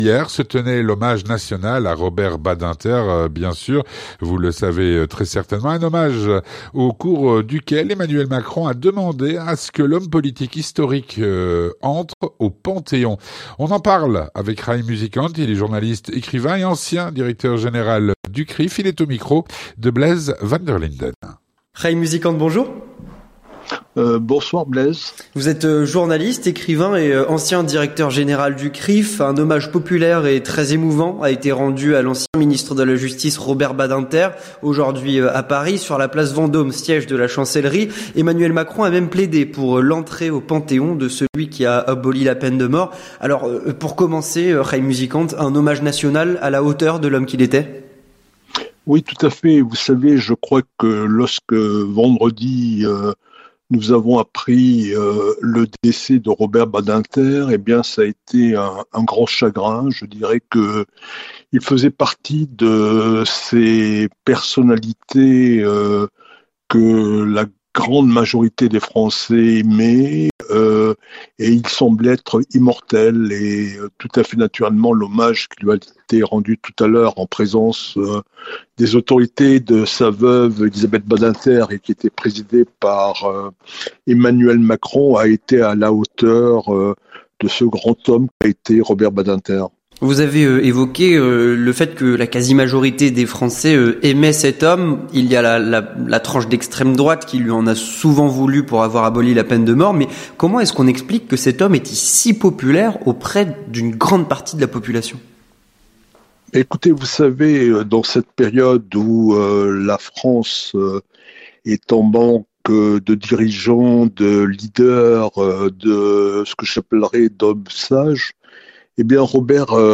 L'entretien du 18H - Robert Badinter au Panthéon ?